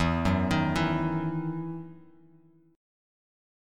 EM7sus2 chord